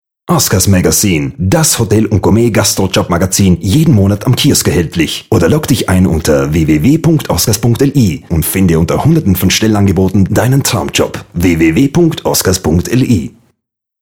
Ola, ist zwar ein Radiospot und schnell gesprochen von mir....